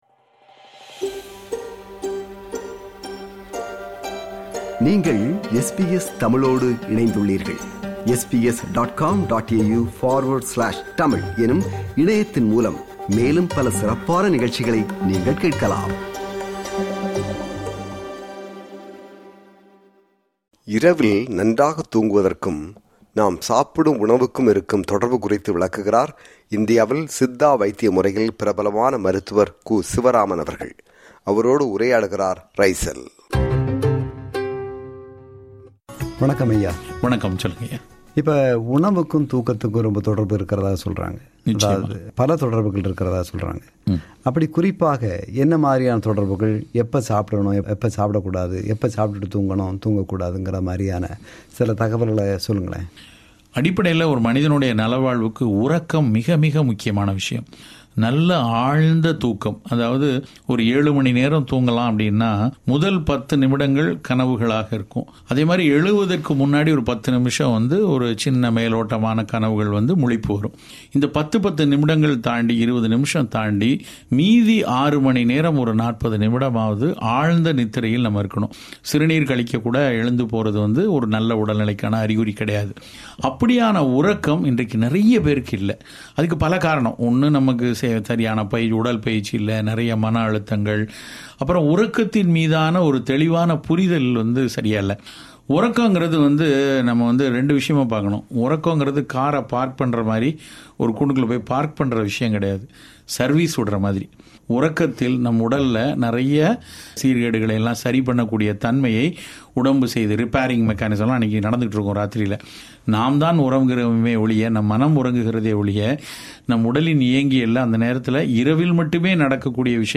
நேர்முகத்தின் மறு ஒலிபரப்பு